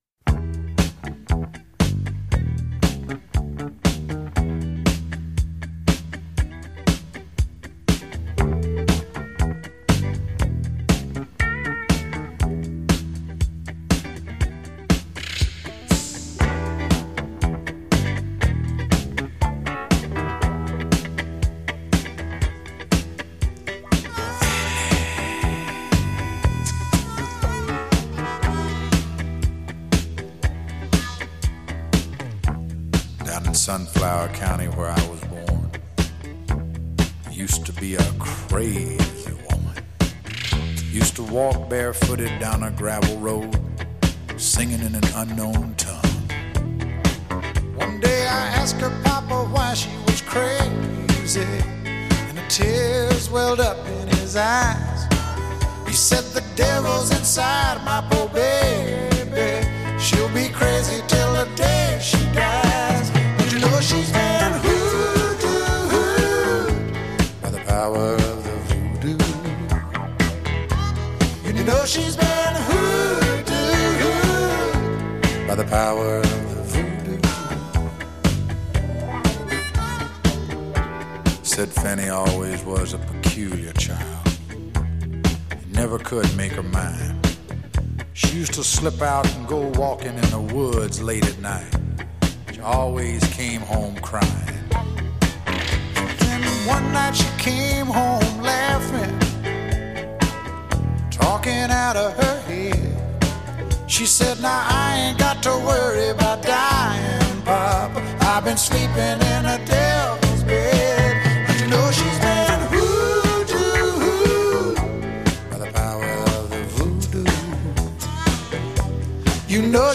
The album mixed country and pop sounds